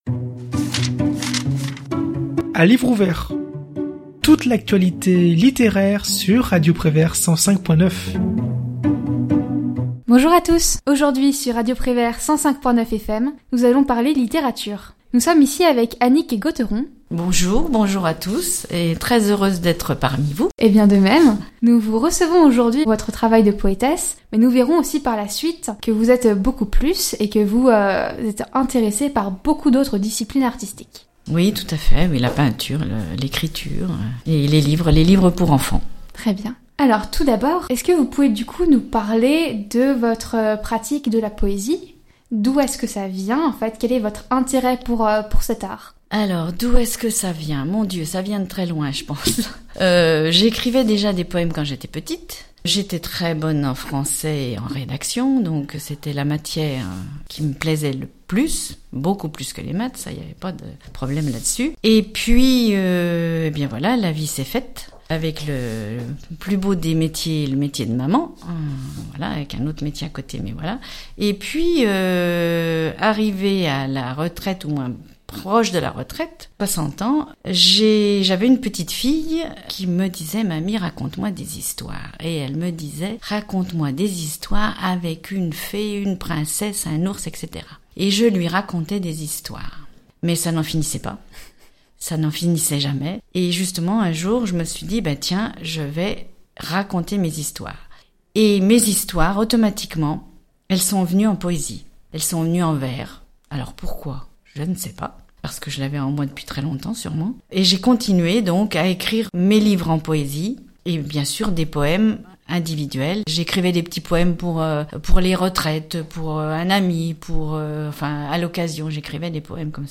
L'Interview de Radio Prévert / Émissions occasionnelles Podcasts